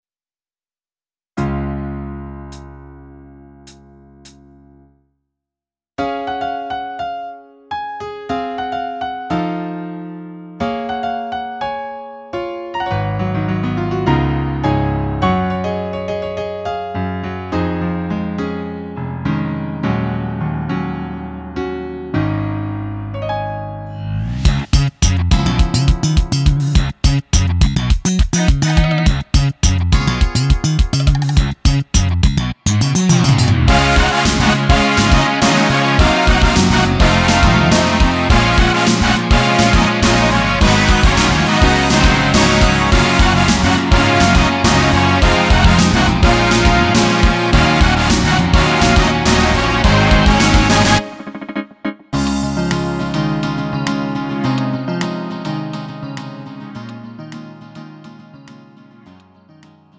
음정 -1키 3:16
장르 가요 구분